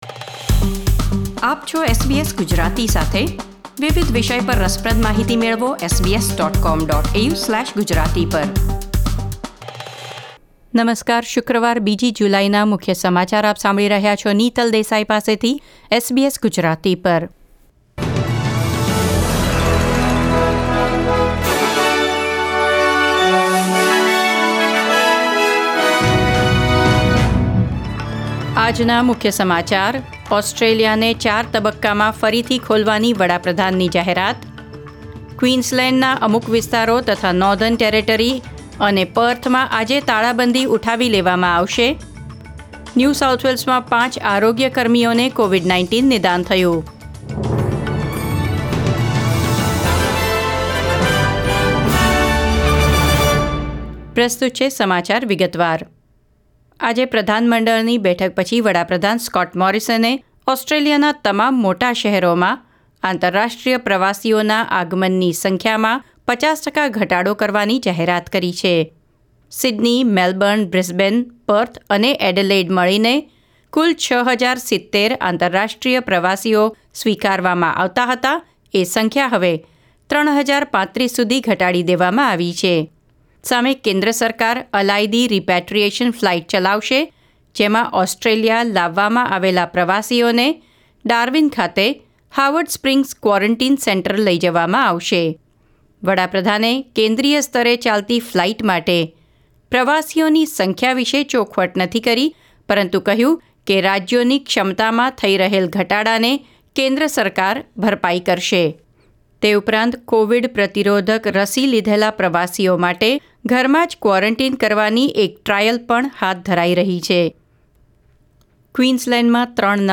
SBS Gujarati News Bulletin 2 July 2021